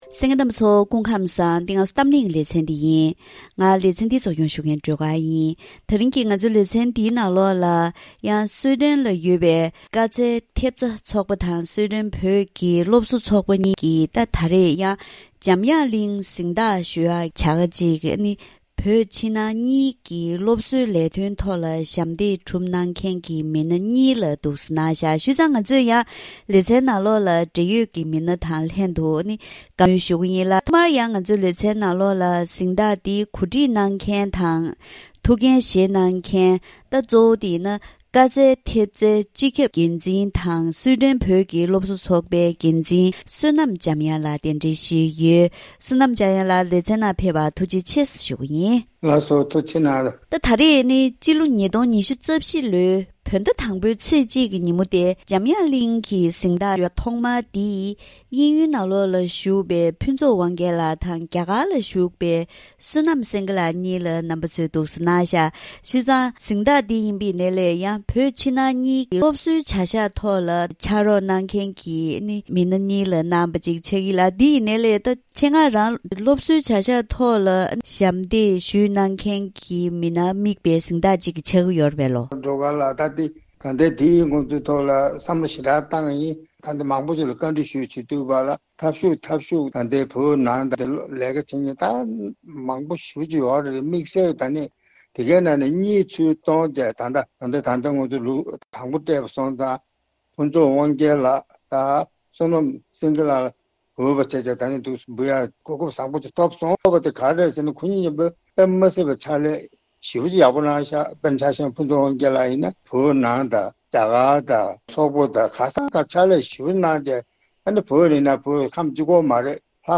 འབྲེལ་ཡོད་མི་སྣ་དང་ལྷན་དུ་བཀའ་མོལ་ཞུས་པ་ཞིག་གསན་རོགས་གནང་།